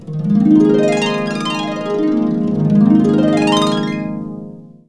HARP A#X ARP.wav